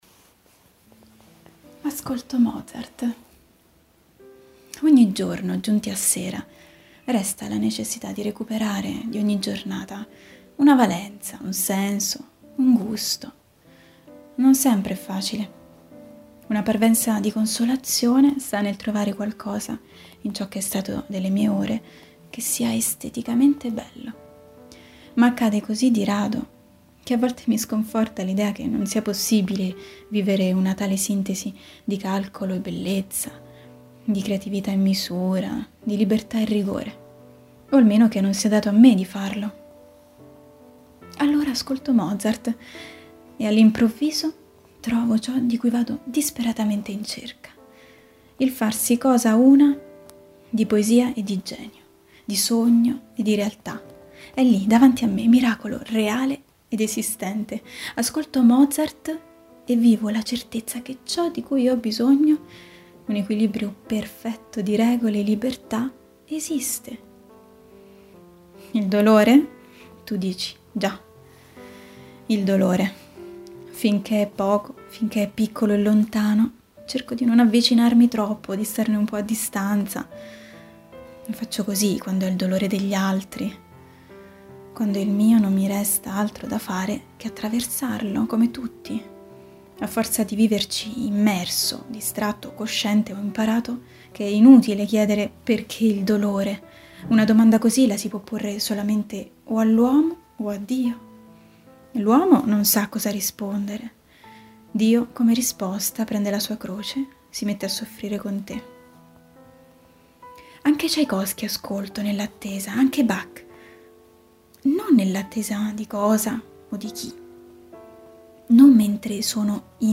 Persona e famiglia > Audioletture
La riflessione di un lettore di Città Nuova su una discussione ampia e diffusa alla quale tutti noi, esseri pensanti, dovremmo sentire il dovere di partecipare, ognuno con il suo portato di pensieri, di convinzioni e di sentimenti (music: Bendsound)